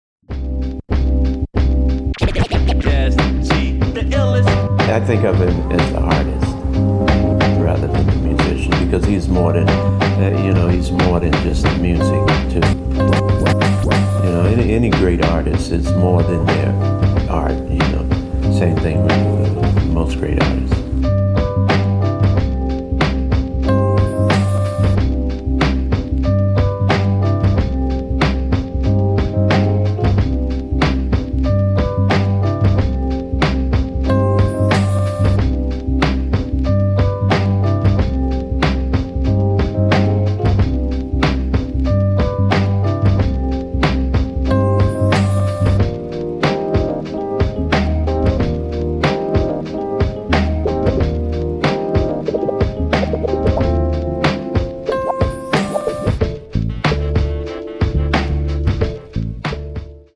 Super limited edition mixtape